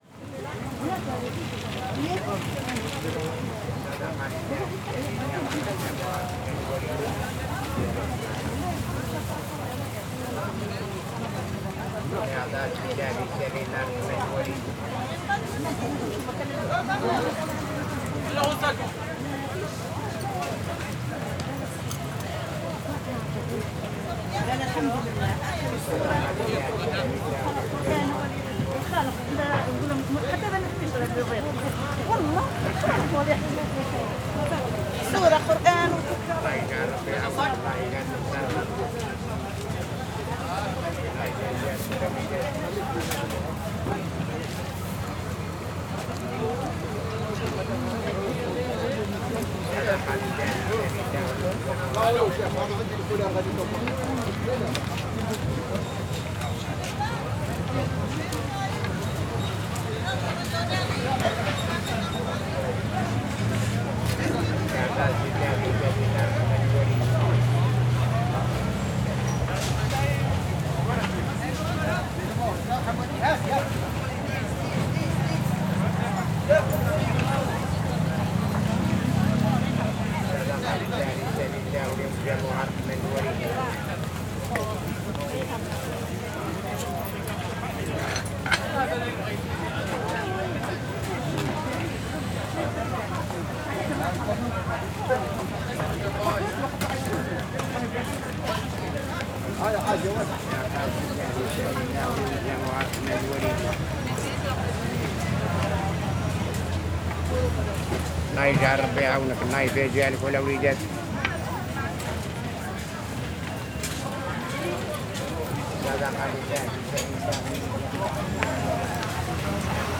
Marché, Boulevard de Belleville, 75020 Paris.
Prise de son : statique devant plusieurs stands de vêtements.
fr CAPTATION SONORE
fr Travaux
fr Voiture
fr Conversation